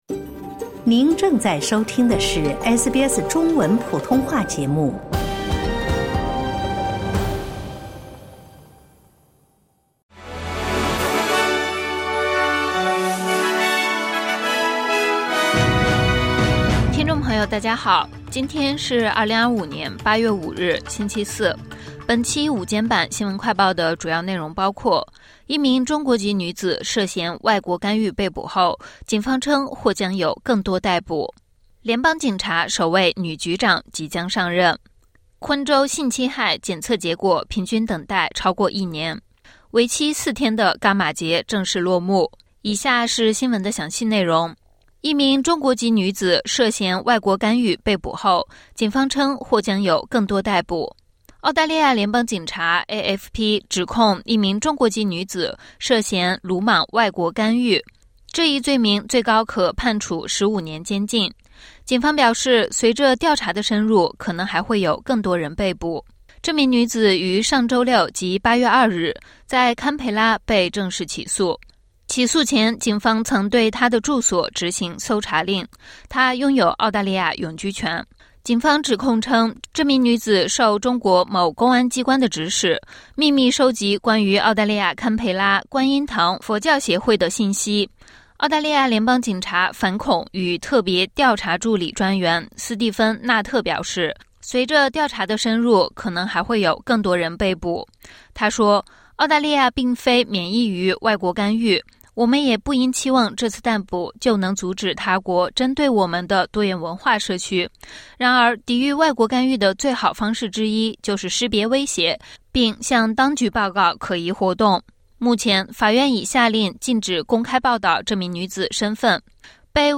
【SBS新闻快报】中国籍女子涉外国干预被捕 警方称调查继续